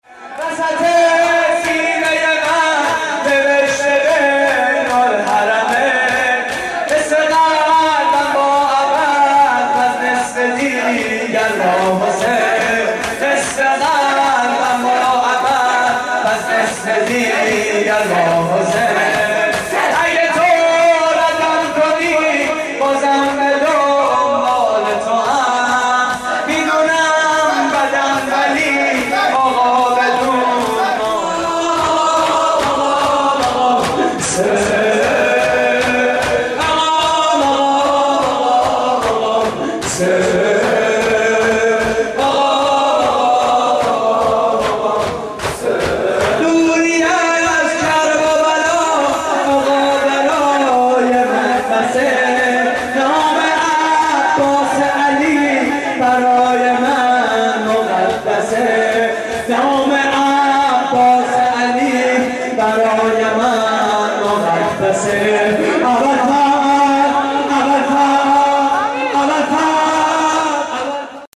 مراسم عزاداری روز سوم ماه محرم / هیئت محبان الرضا (ع) - مشهد مقدس؛ 18 آذر 89
صوت مراسم:
شور: وسط سینه‌ی من نوشته بین الحرمین؛ پخش آنلاین |